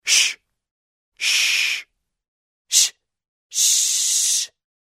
Звуки тс-с
Звук шшш призывающий людей замолчать